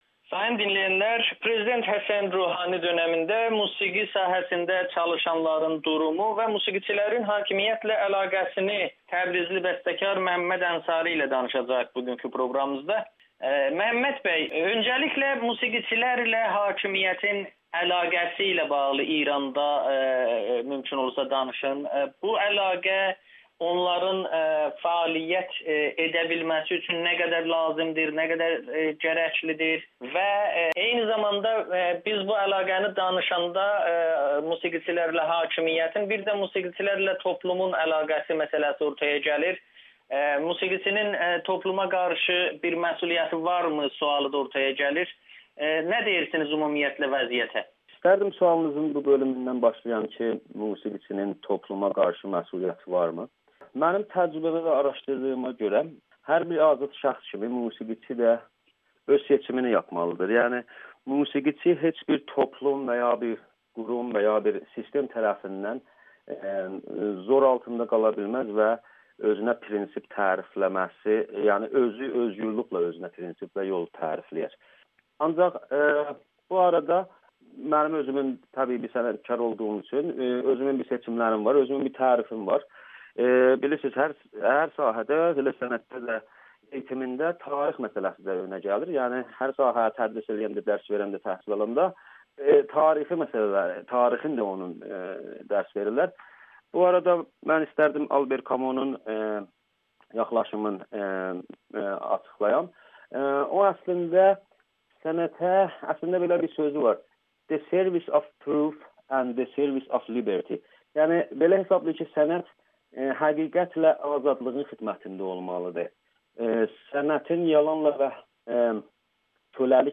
Təbrizli bəstəkar Amerikanın Səsinə müsahibədə İranda musiqiçilərin hakimiyyətlə əlaqəsini dəyərləndirməklə yanaşı, Həsən Ruhani dönəmində musiqi sahəsində nisbi yumşalmaya dair xəbərlərə münasibət bildirib.